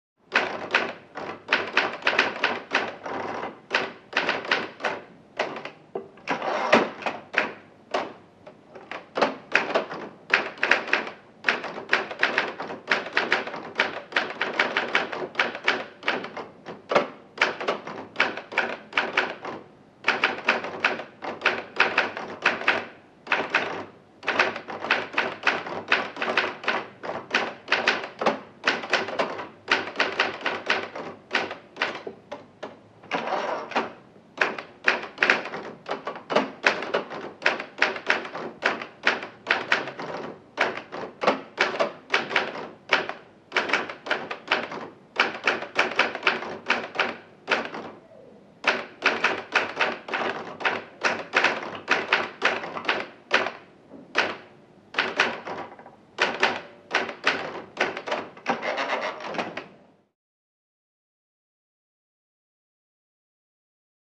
Typewriters|Manual | Sneak On The Lot
Large Older Office Or Newsroom; Busy Voices Manual Typewriter, Old Type Desk Phones Ring General Background Movement, Tiled Room, Close To Distant Perspective.